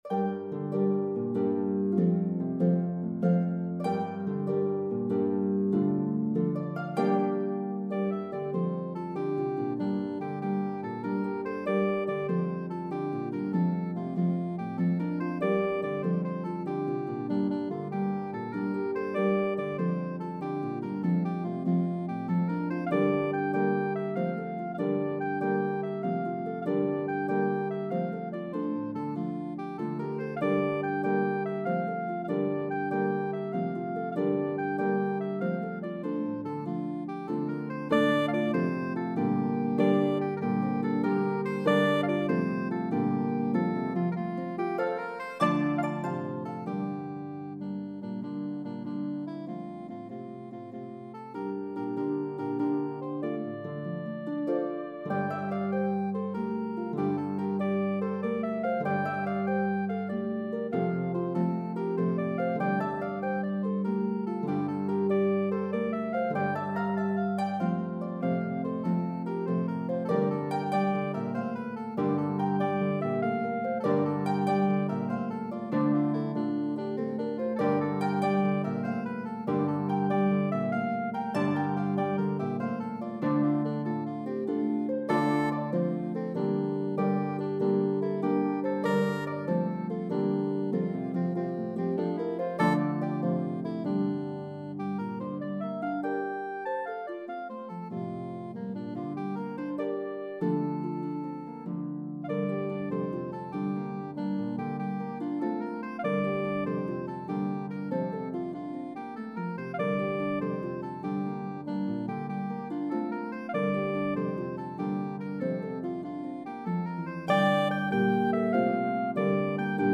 One of the most energetic Step Dances in Slip Jig form.